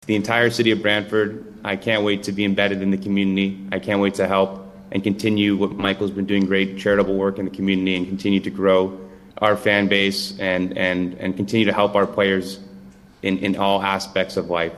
Edmonton Oilers star Zach Hyman and his family, the new owners, confirmed the decision during a news conference and said he looks forward to working with the community moving forward.